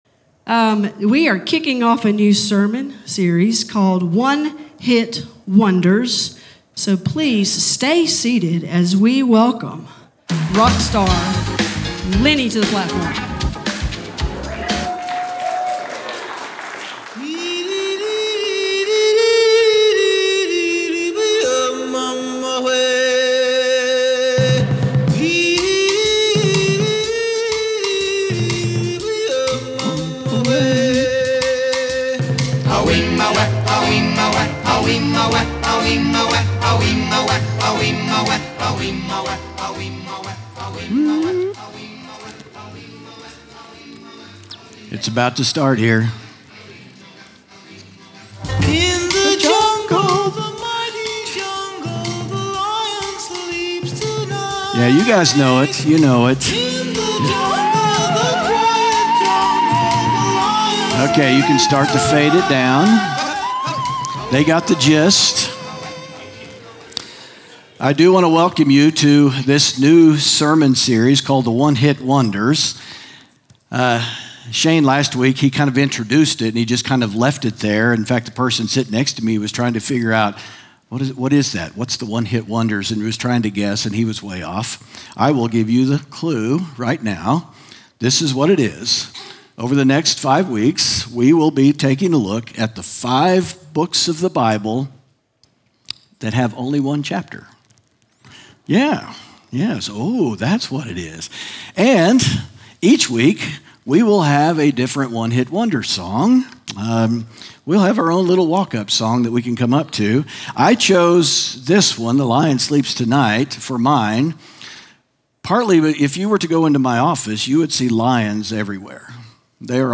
sermon audio 1012.mp3